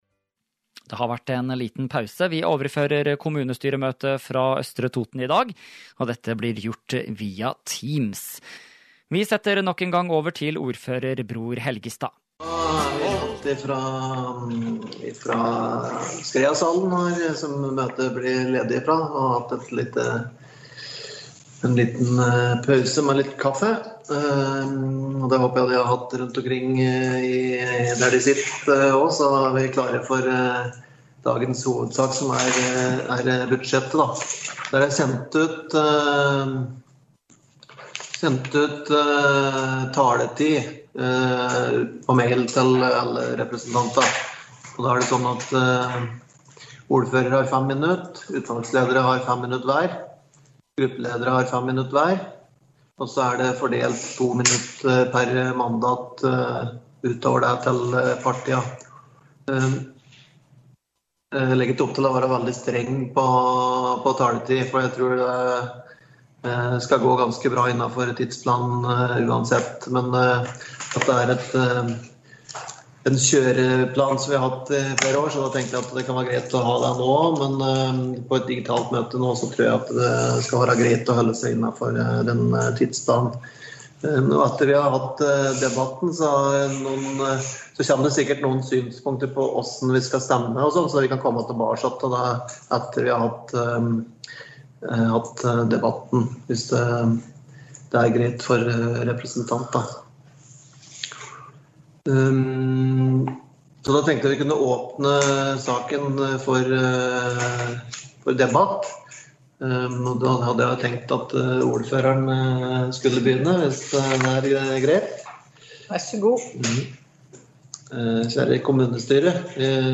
Kommunestyremøte fra Østre Toten 15. desember – Lydfiler lagt ut | Radio Toten